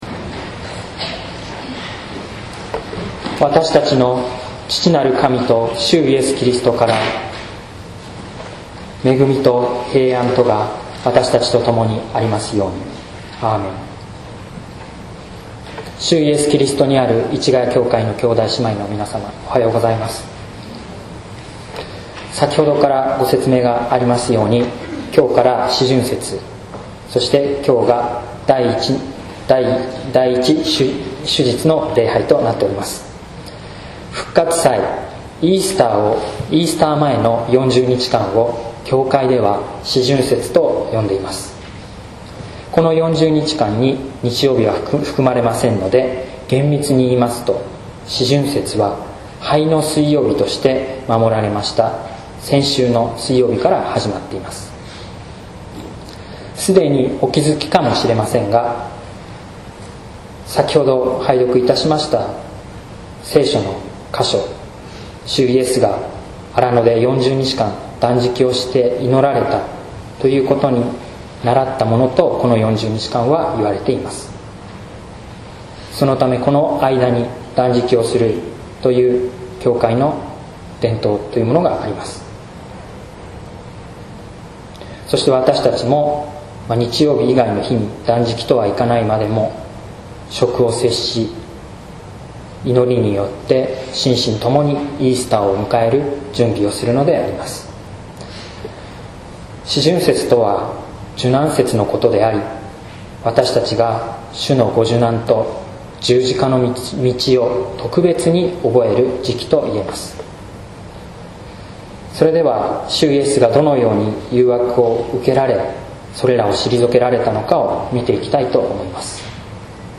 説教「荒野の誘惑」（音声版）